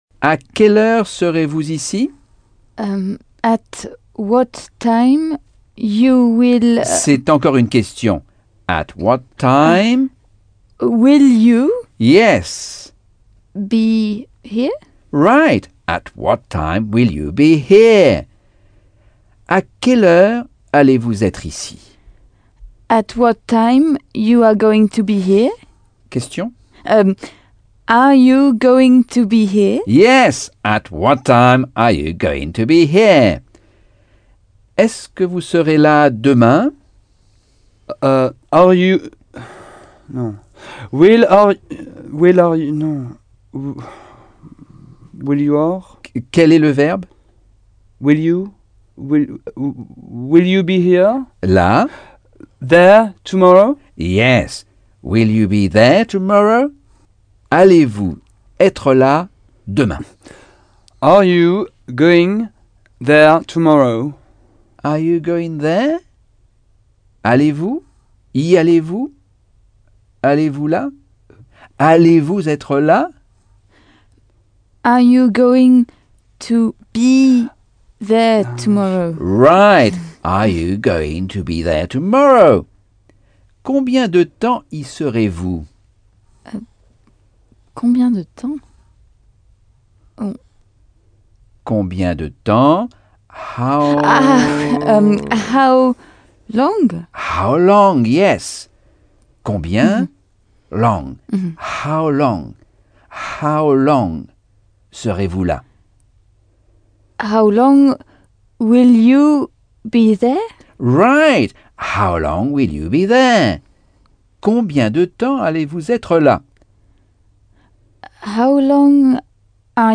Leçon 2 - Cours audio Anglais par Michel Thomas